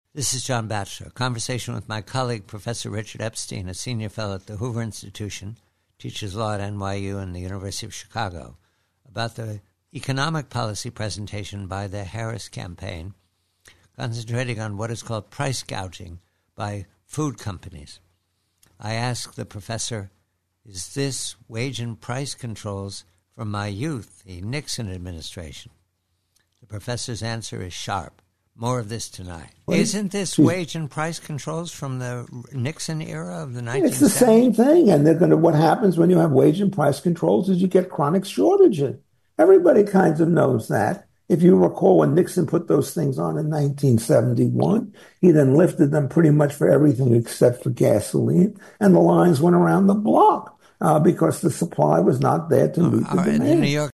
PREVIEW: Conversation with colleague Richard Epstein of the Hoover Institution regarding the Harris presentation that price gouging by food corporations is the driver of inflation at the marketplace.